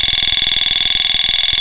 Sirena Multitonal 8 Sonidos
102dB